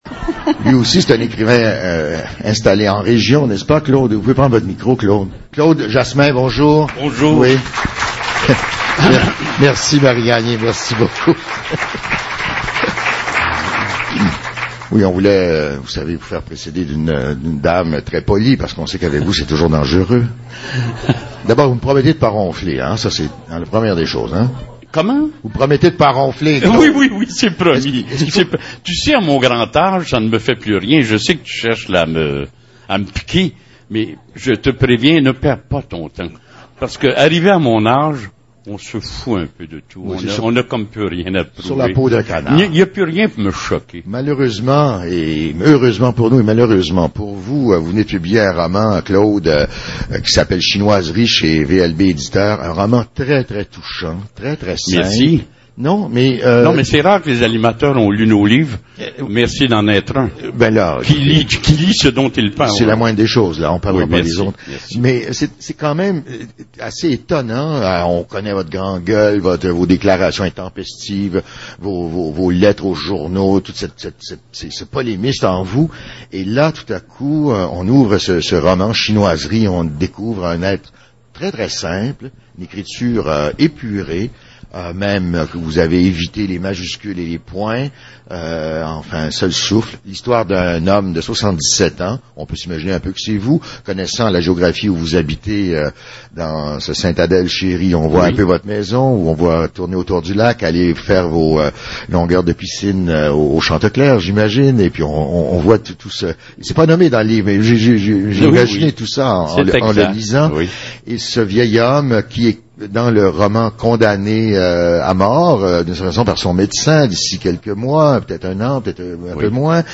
Jasmin en entrevue avec Raymond Cloutier à l'émission Tour de piste - 2007-04-01 Clip audio : Le lecteur Adobe Flash (version 9 ou plus) est nécessaire pour la lecture de ce clip audio.